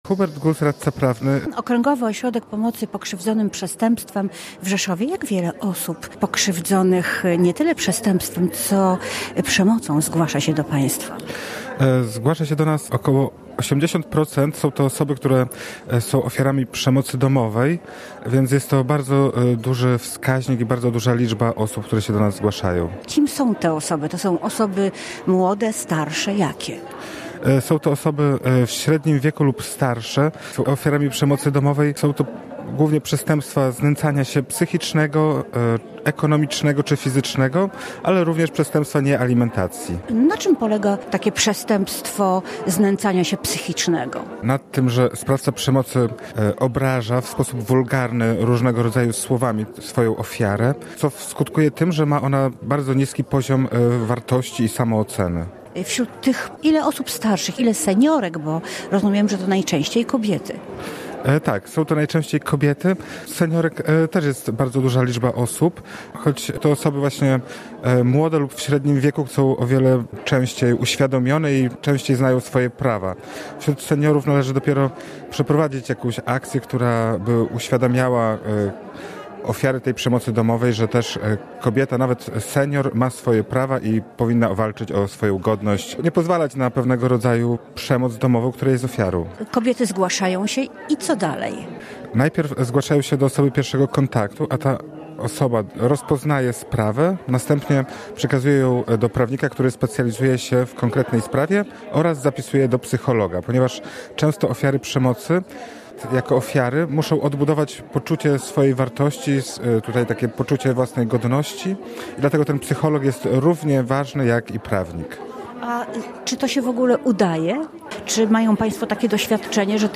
Przemoc dotyka też seniorów. Rozmawia